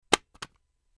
Звук упавшего смартфона выскользнул из рук и ударился о землю